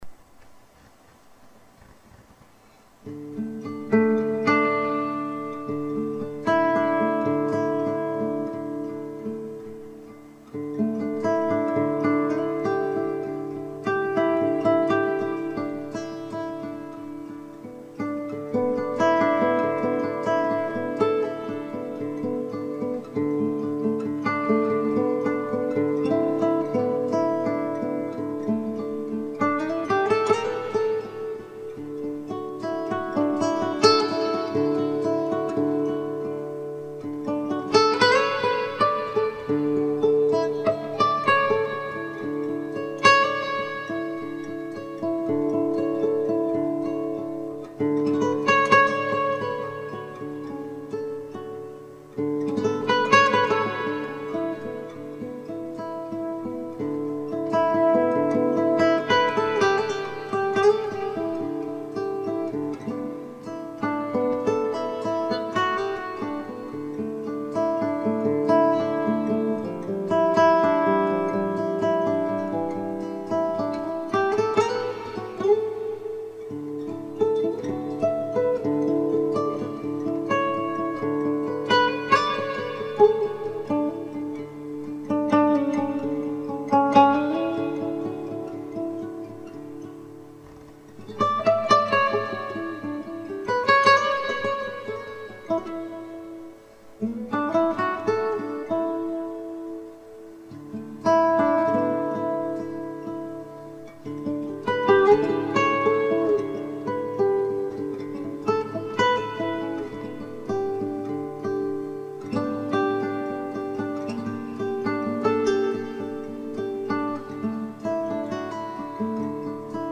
une douceur accoustique télécharger en mp3